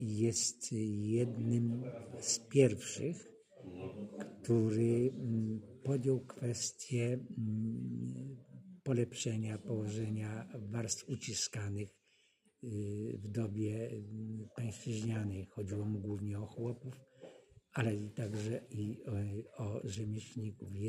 19 marca w Studenckim Klubie WSPAK w Kielcach odbyło się spotkanie poświęcone ks. Piotrowi Ściegiennemu – działaczowi społecznemu i niepodległościowemu XIX wieku.